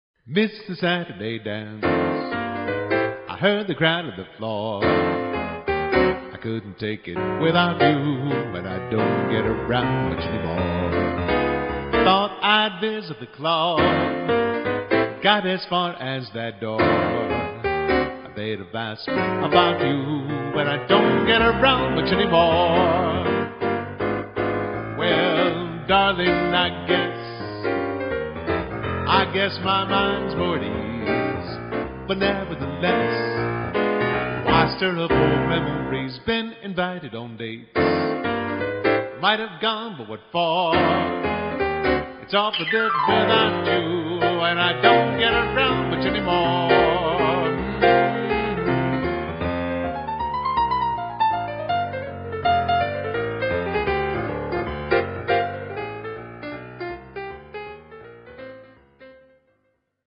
Piano / Keyboard with Vocals:
Swing